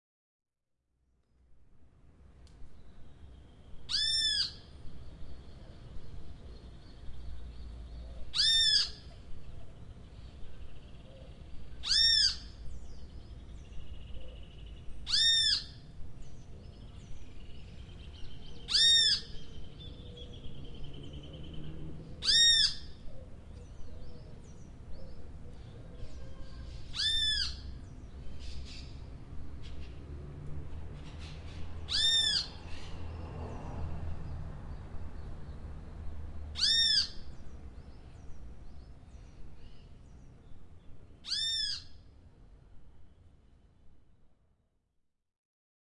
现场录音.A O " 现场录音.AO.Bird.4
描述：鸟类宣言坚强，但友好。
Tag: 场记录 自然 户外 声音 zoomh4